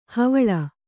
♪ havaḷa